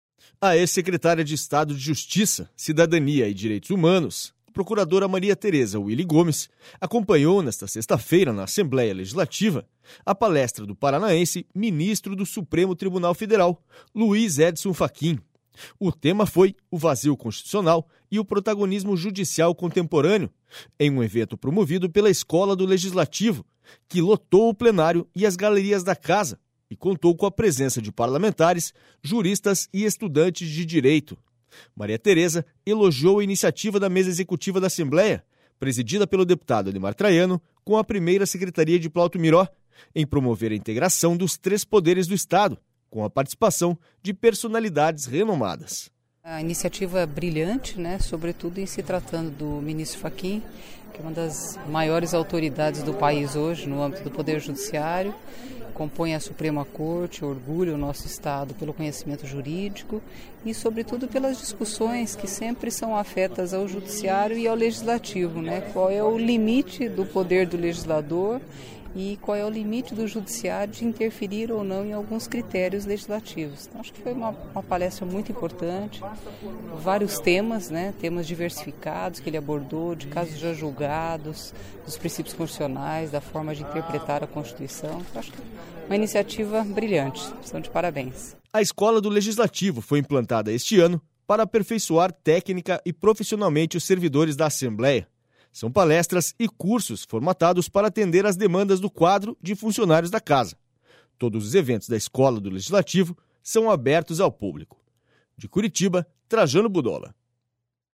SONORA MARIA TEREZA UILE GOMES